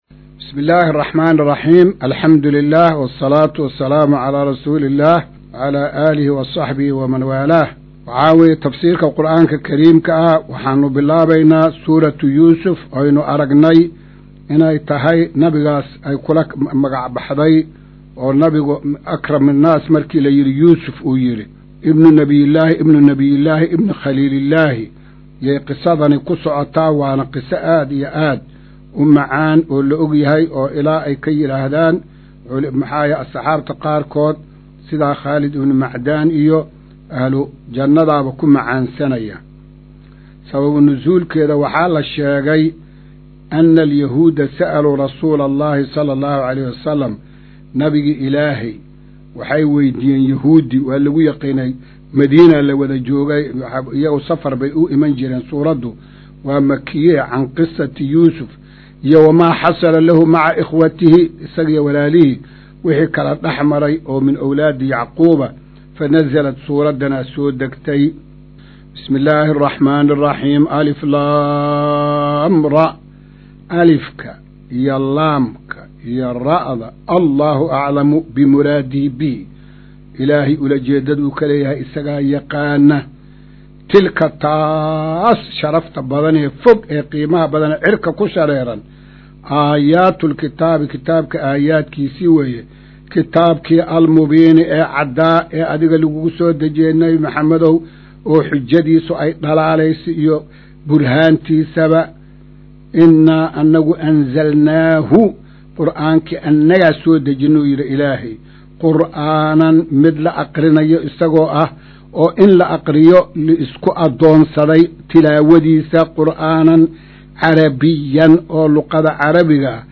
Maqal:- Casharka Tafsiirka Qur’aanka Idaacadda Himilo “Darsiga 117aad”